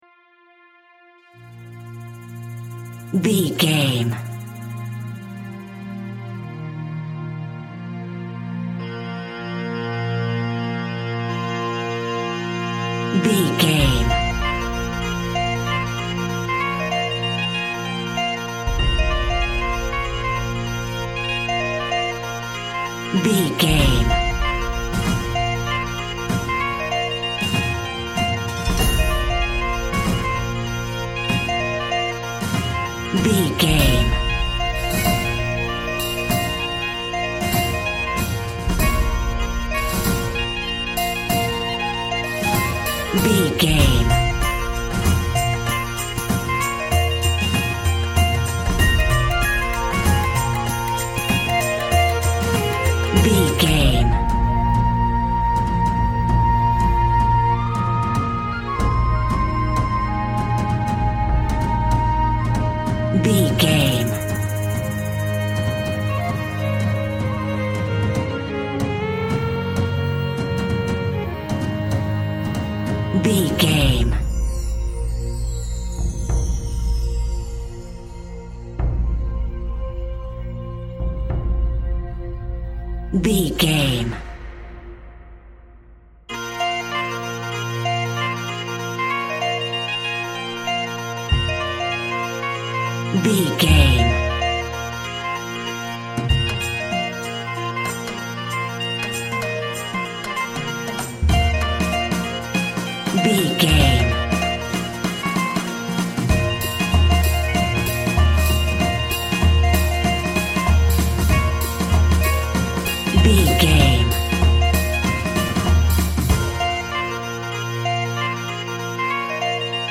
A great piece of Gaelic Celtic music with Irish charm!
Uplifting
Mixolydian
B♭
folk music
strings
percussion